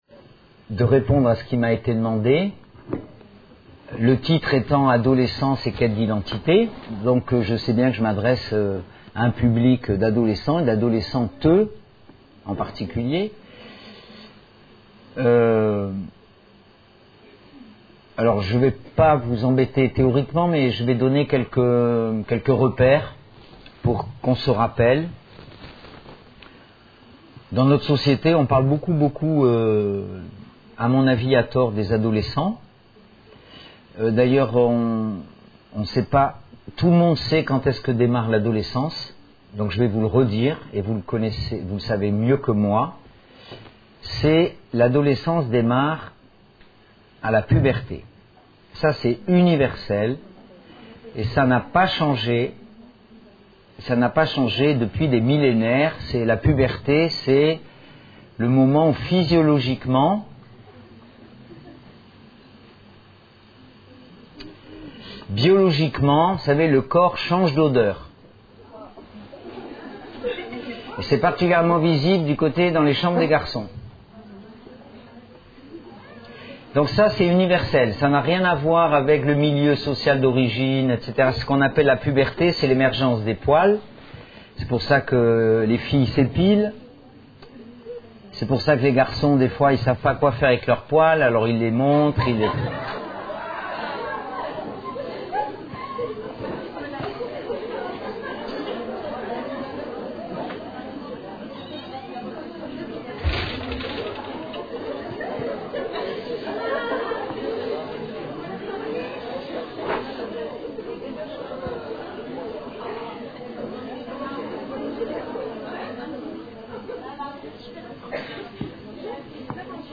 Une conférence de l'UTLS au Lycée Adolescence : quête d'identité